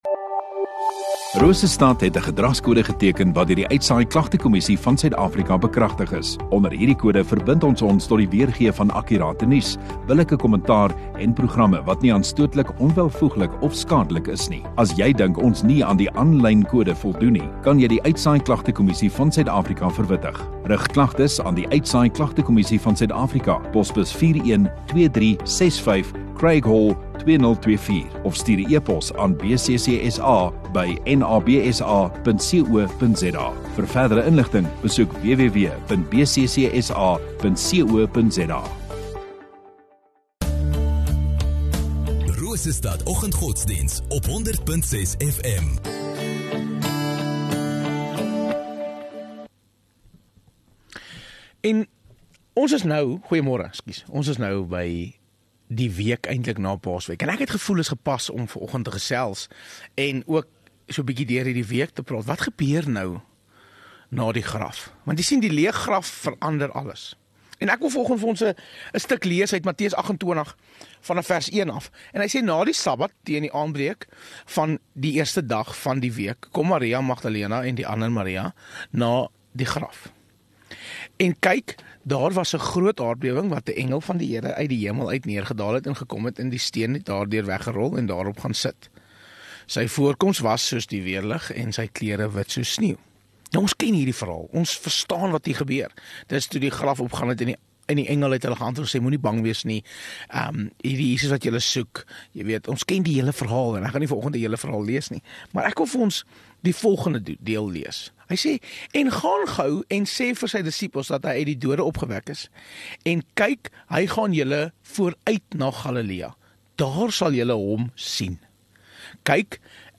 13 Apr Maandag Oggenddiens